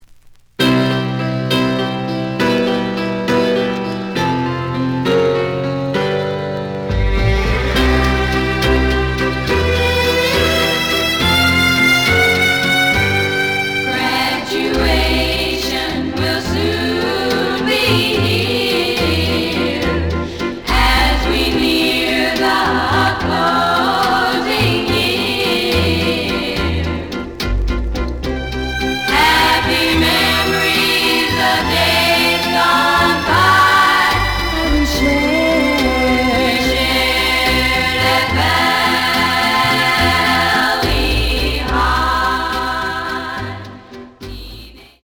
The audio sample is recorded from the actual item.
●Genre: Rock / Pop
Slight edge warp. But doesn't affect playing. Plays good.)